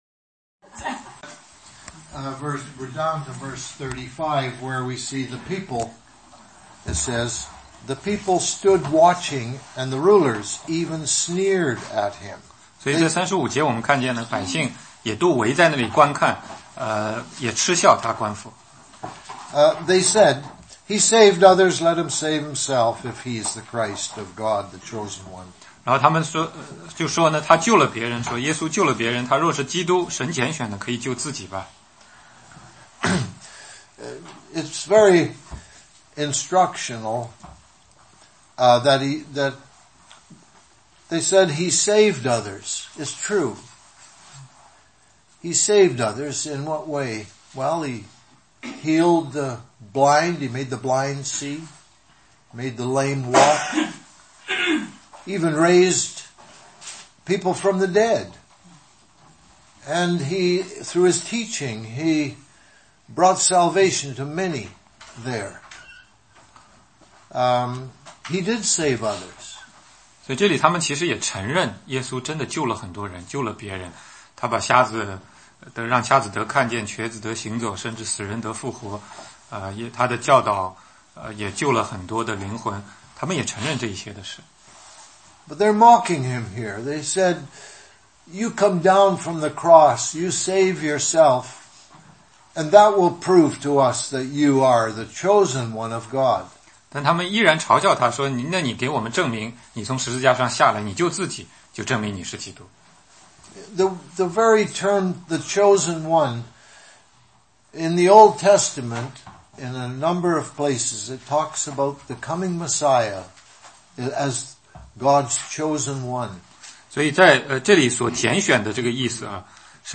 16街讲道录音 - 路加23 33_43,十字架边的俩个强盗